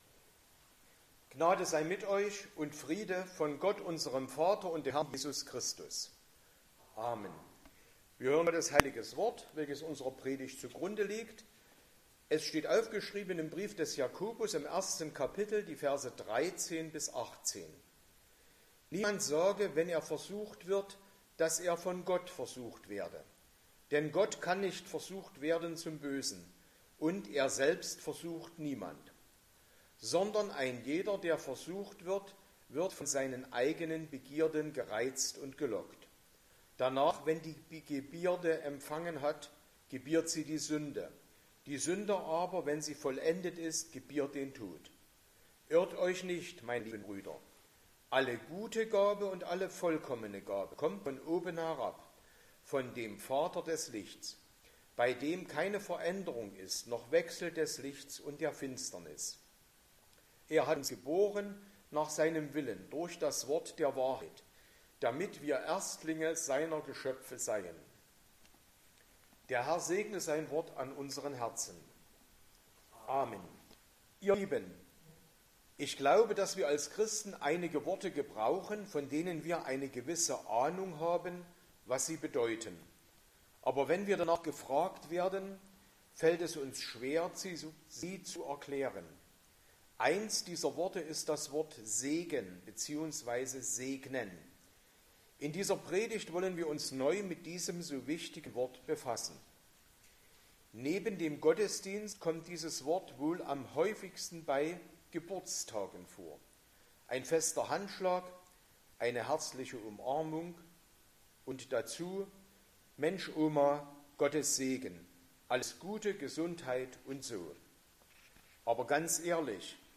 Passage: Jakobus 1,13-18 Verkündigungsart: Predigt